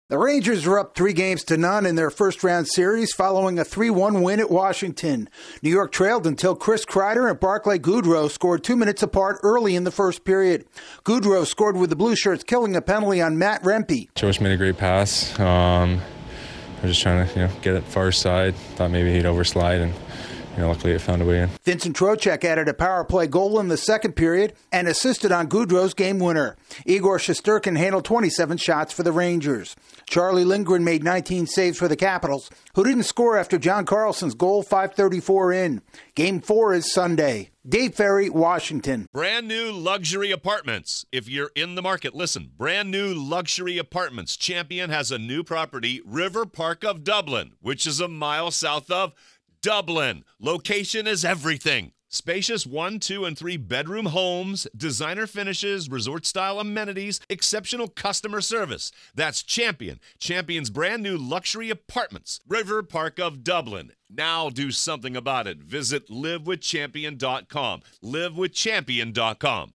The Rangers fall behind early before beating the Capitals for the third time this week. AP correspondnet